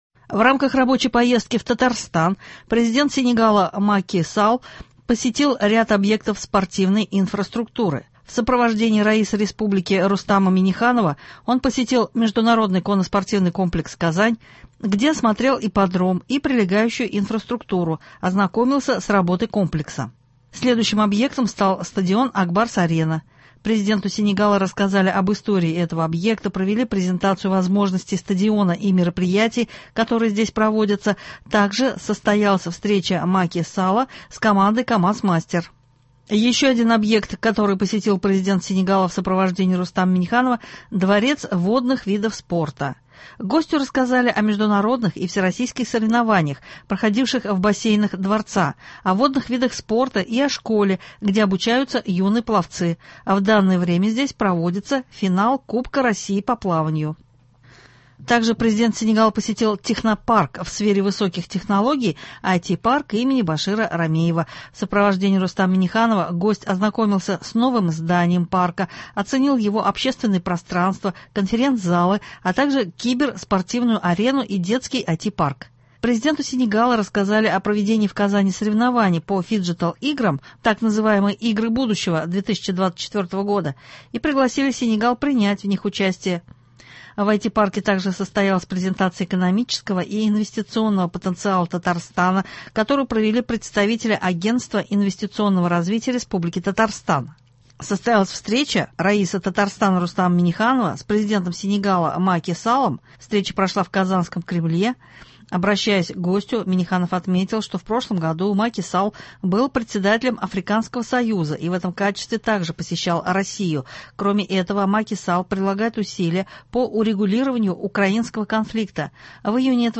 Новости (27.07.23)